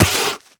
biter-roar-4.ogg